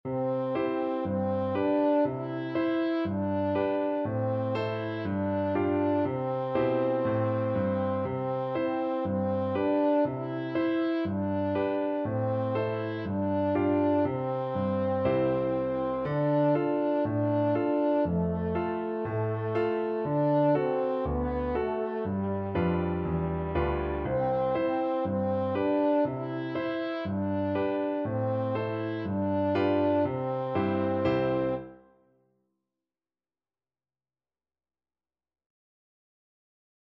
French Horn
C major (Sounding Pitch) G major (French Horn in F) (View more C major Music for French Horn )
Allegro (View more music marked Allegro)
4/4 (View more 4/4 Music)
G4-E5
Traditional (View more Traditional French Horn Music)